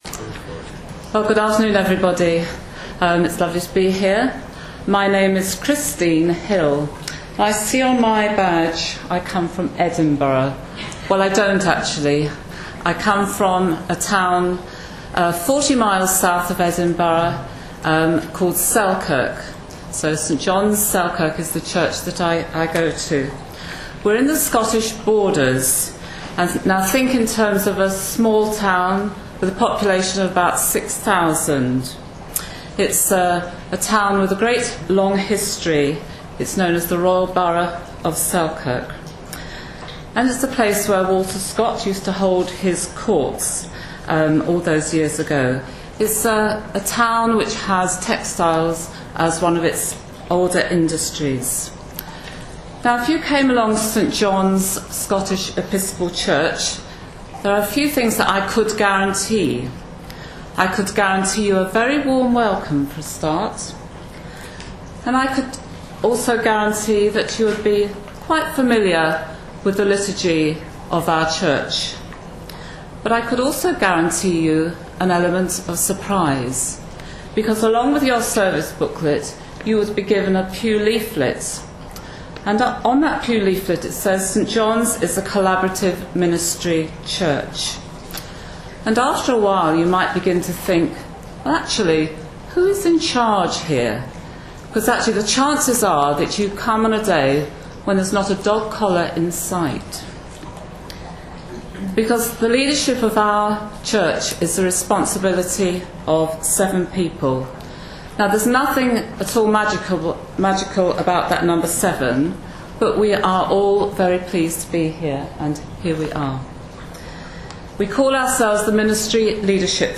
Mission Shaped Collaborative Ministry. The Ministry Leadership Team from St John's Selkirk tell their story at the 2015 Shared Ministry Conference.